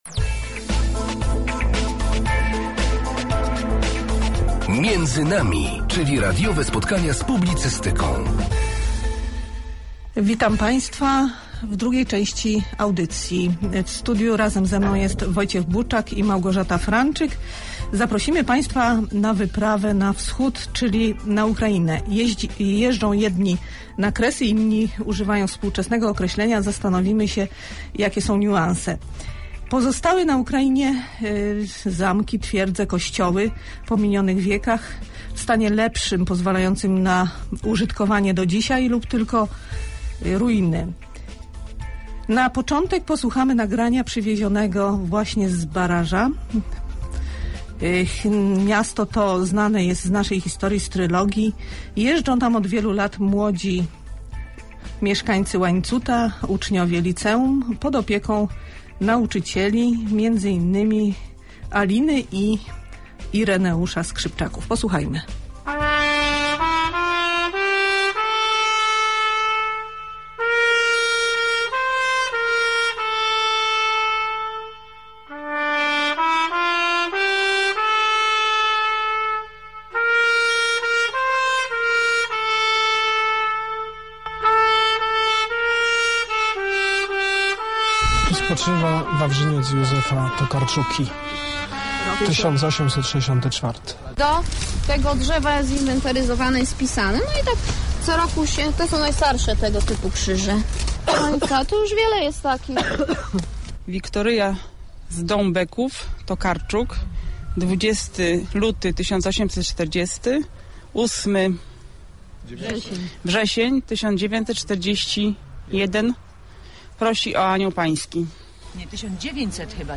Reportaż Polskiego Radia Rzeszów wyemitowany 15 maja 2007r. o godz. 19 05